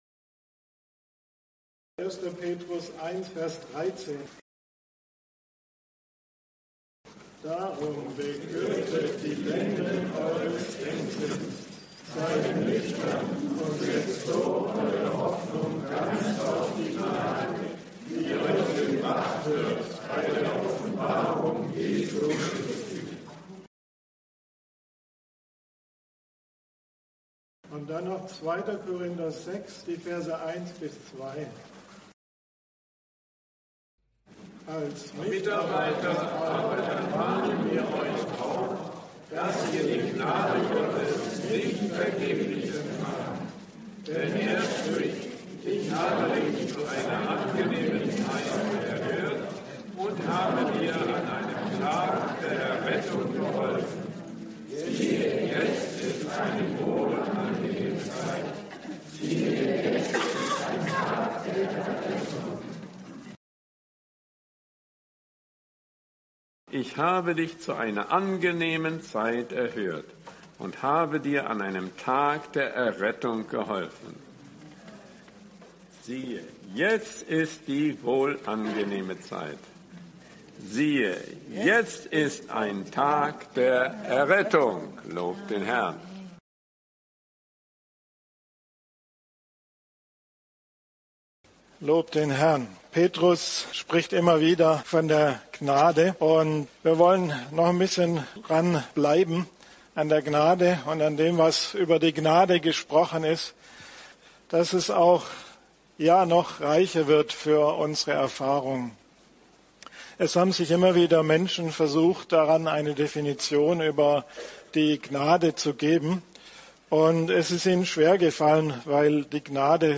Gemeindeversammlung